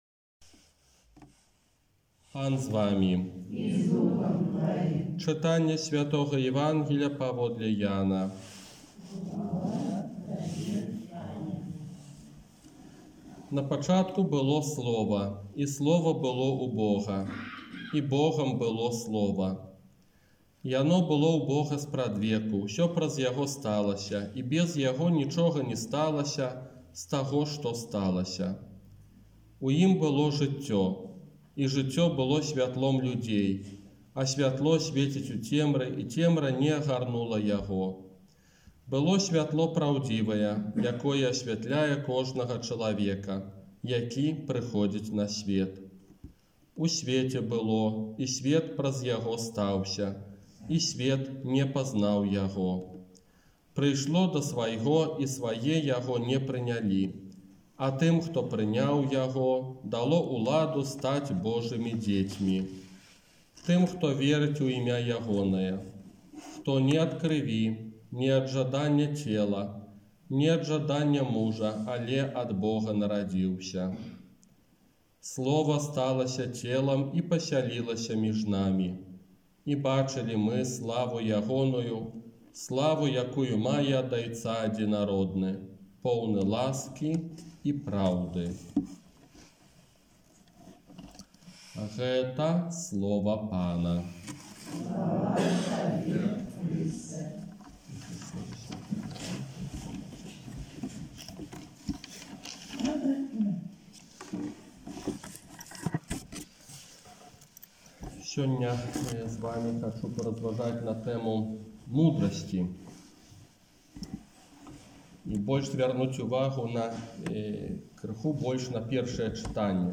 ОРША - ПАРАФІЯ СВЯТОГА ЯЗЭПА
Казанне на другую нядзелю Божага Нараджэння